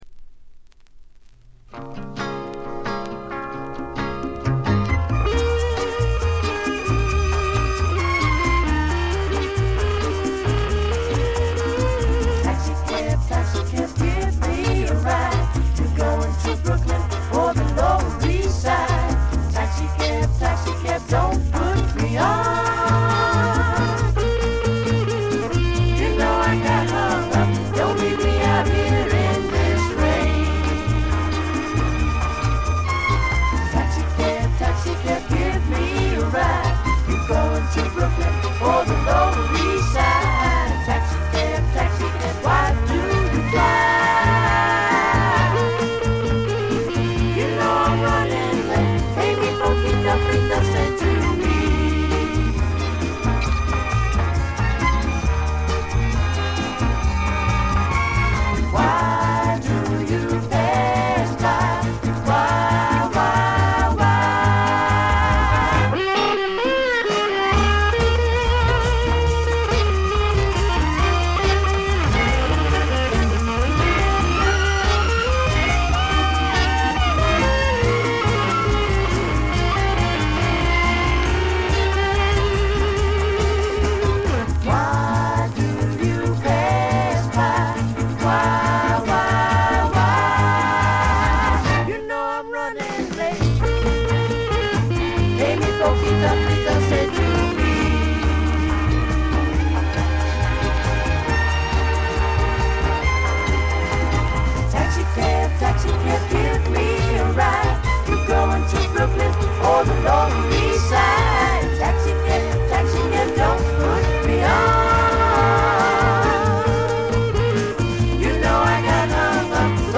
軽微なバックグラウンドノイズ。
このデビュー作はフォーキーなグッタイム・ミュージックの傑作です。
試聴曲は現品からの取り込み音源です。